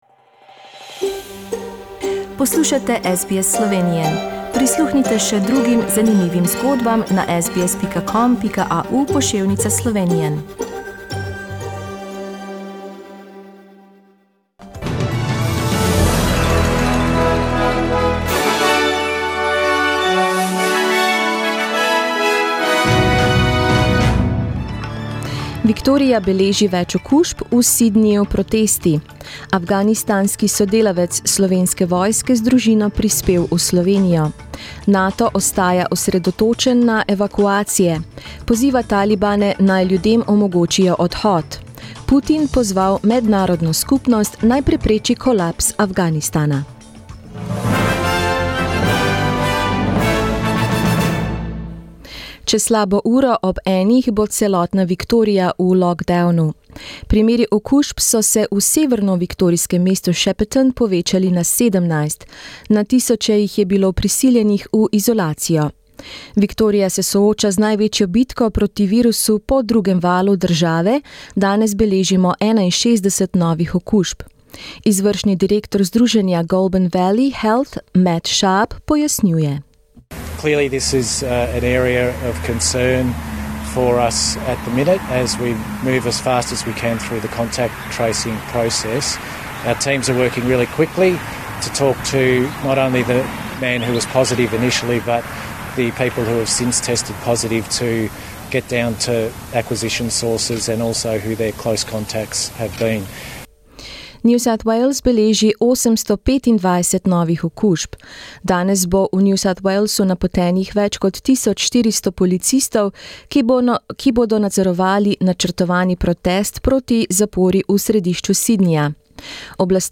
Listen to news in Slovenian.